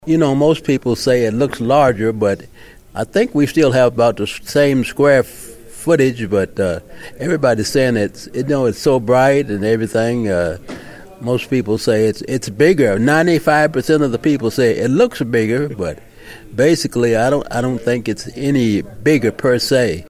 During a Community Broadcast sponsored by United Southern Bank, Elkton councilman Frank McReynolds said he’s heard a lot of positive feedback on the updated facility.